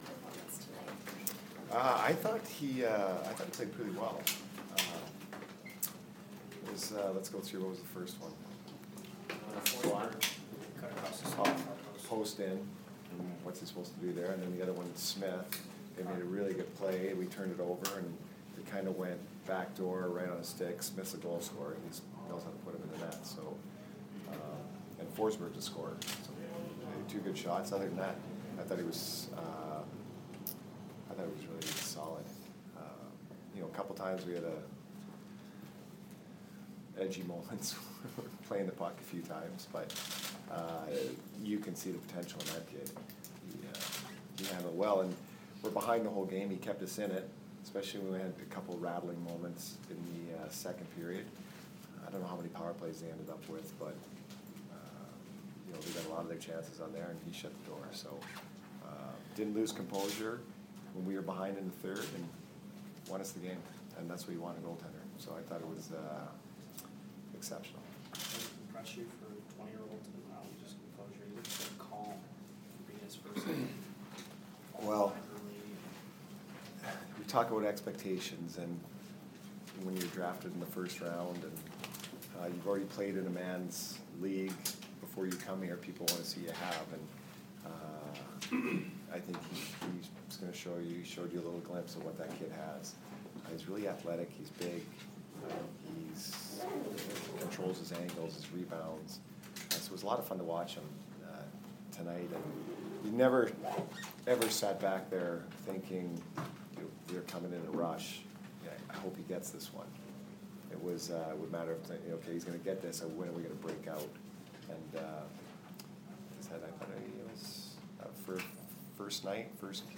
Jon Cooper speaks to the media - 9/23 vs. NSH